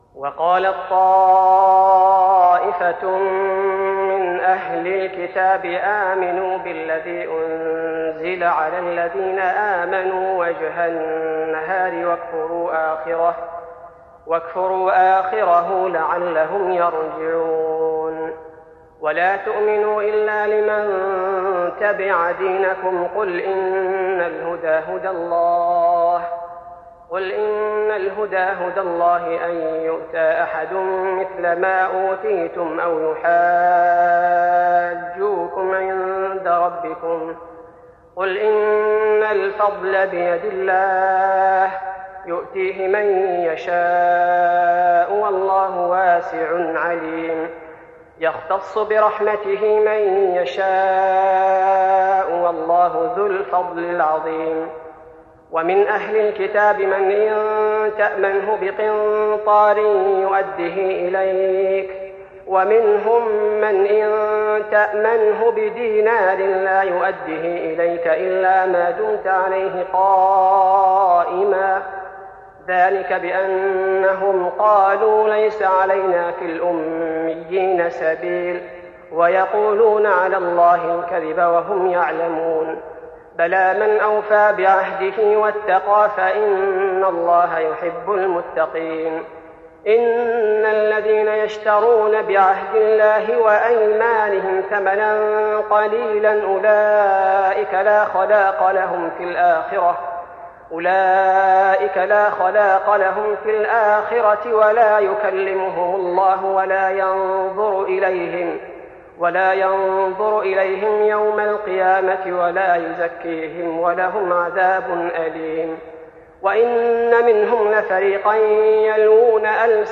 تراويح رمضان 1415هـ من سورة آل عمران (72-138) Taraweeh Ramadan 1415H from Surah Aal-i-Imraan > تراويح الحرم النبوي عام 1415 🕌 > التراويح - تلاوات الحرمين